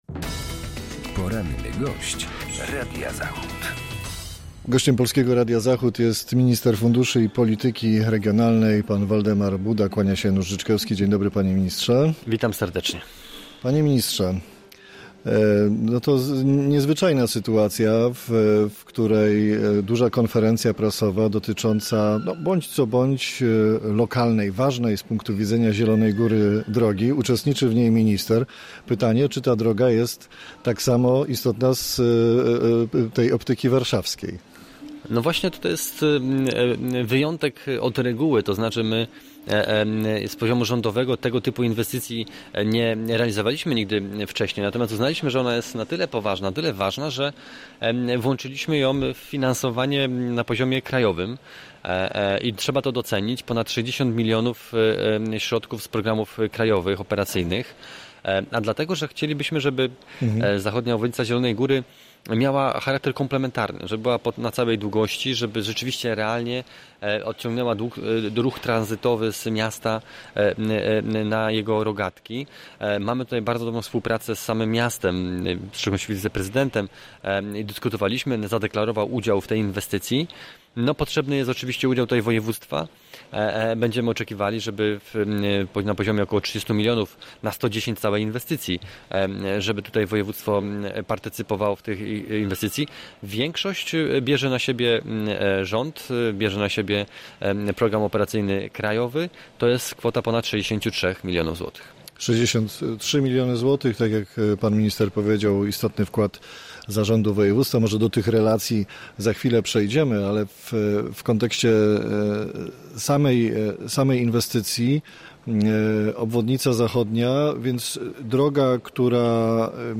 Z wiceszefem Ministerstwa Funduszy i Polityki Regionalnej rozmawia